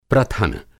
Enviar uma foto Sugerir uma tradução Adicione às suas revisões Eliminar das suas revisões प्रथन प्रथन Aprenda Hindi com falantes nativos Nova pesquisa? pesquisa Pesquisar com voz ... ou folhear o dicionário Descubra a Índia